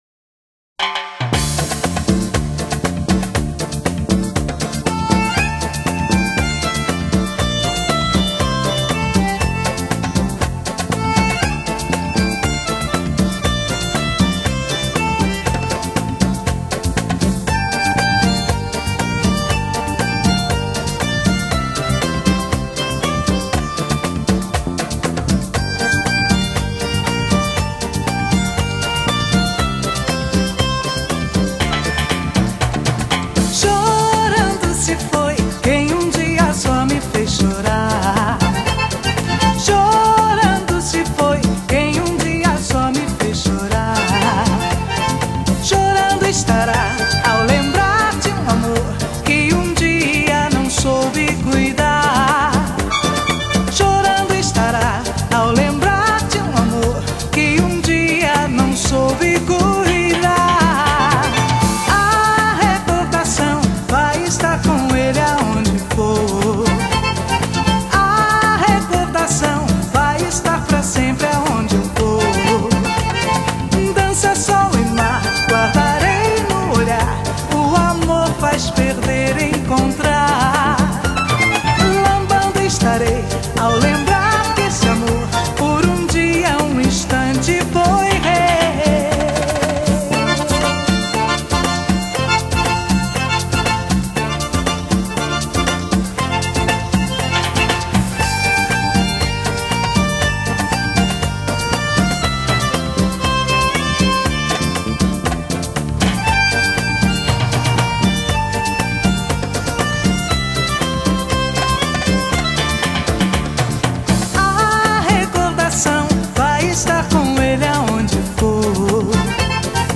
Genre: Latin, Dance, Disco